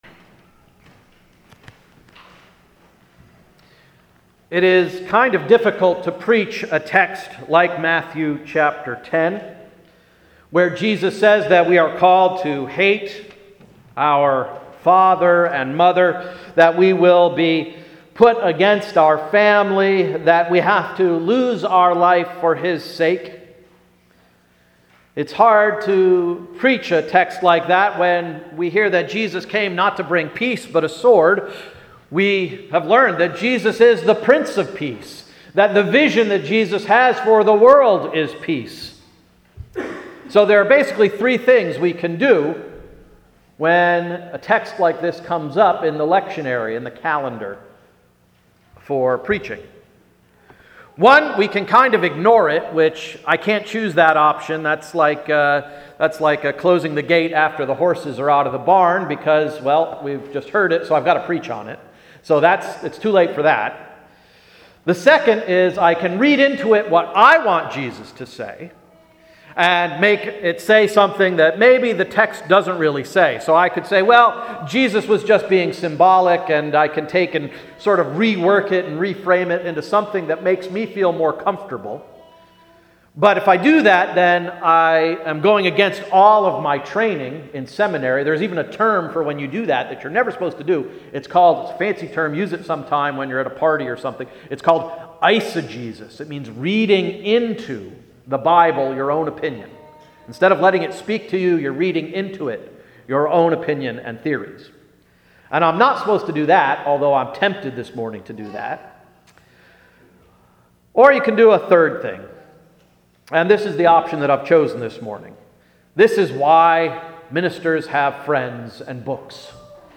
Sermon of June 22, 2014–“School’s Out!”